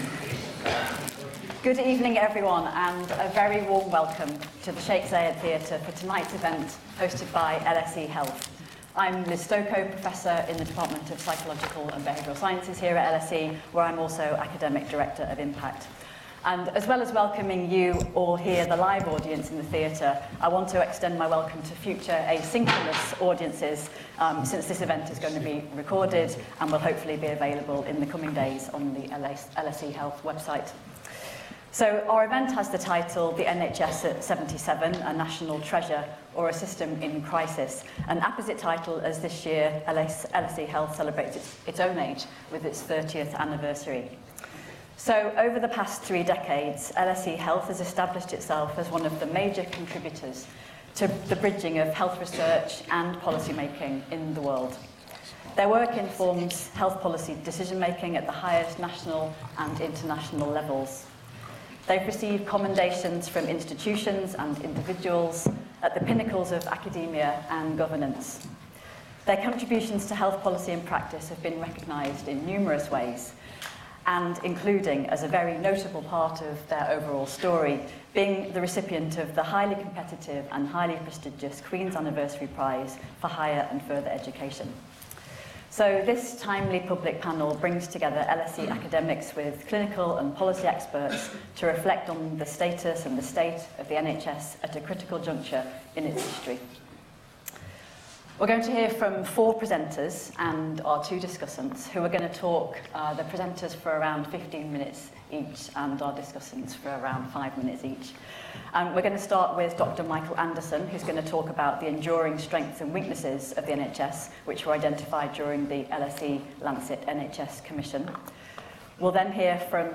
In celebration of LSE Health's 30th anniversary in 2025, this timely public panel-session brought together LSE academics with clinical and policy experts to reflect on the status of the NHS at a critical juncture in its history.